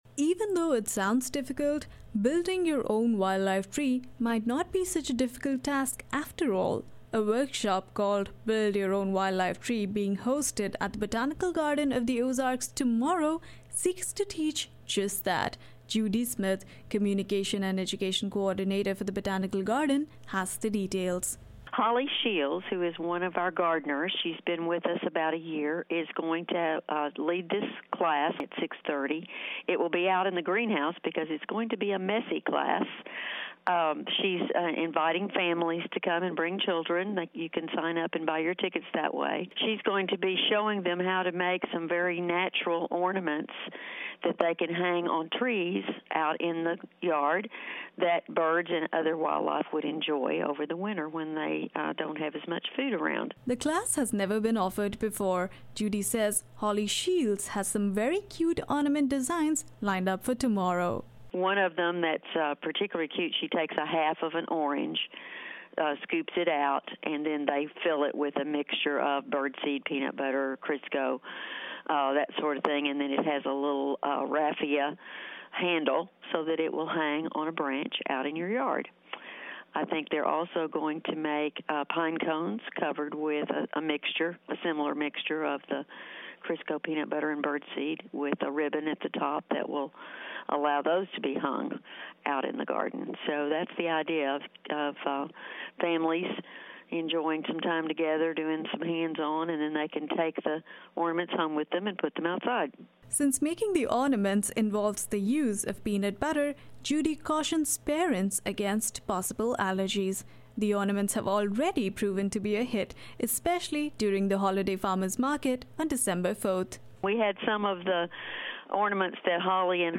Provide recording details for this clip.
More from the session at the Fayetteville Public Library, including questions from the audience, can be heard here.